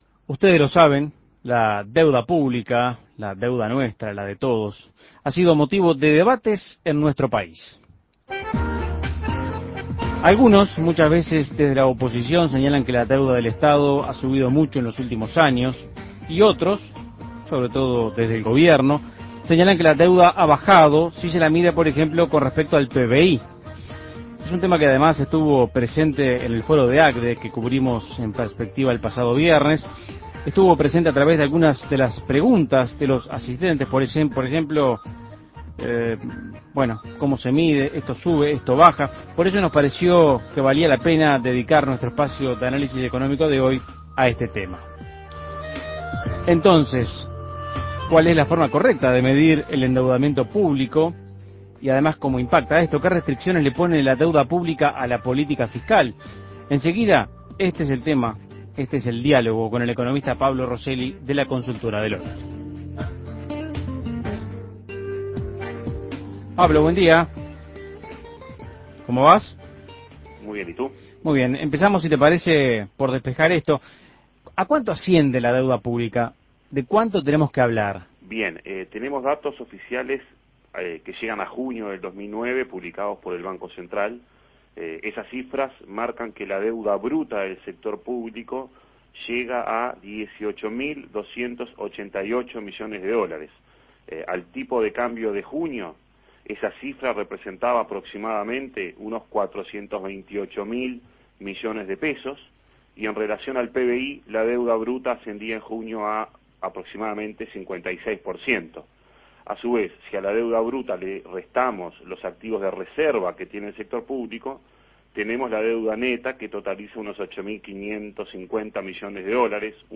Análisis Económico La deuda pública en debate - ¿Cuál es la forma correcta de medirla?